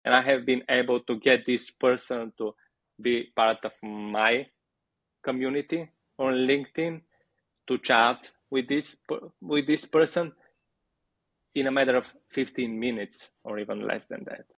deep-dive conversation